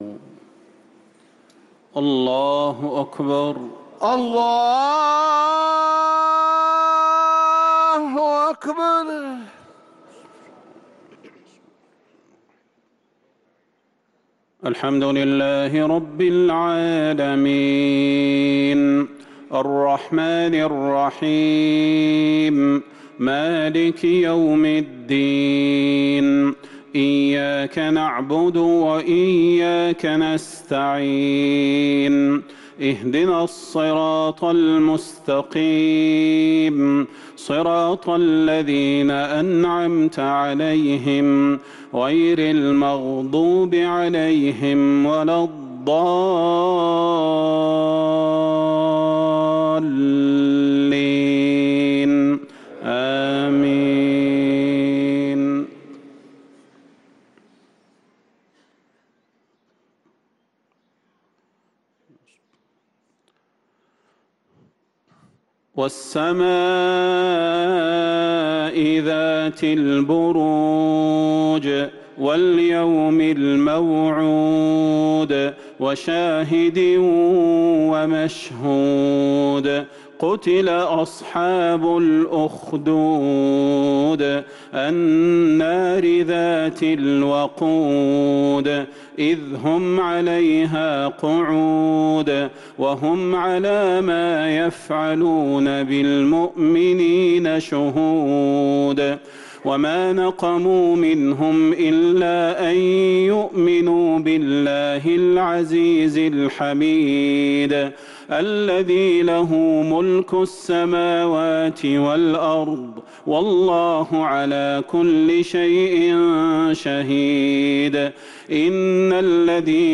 صلاة المغرب للقارئ صلاح البدير 25 ربيع الآخر 1445 هـ
تِلَاوَات الْحَرَمَيْن .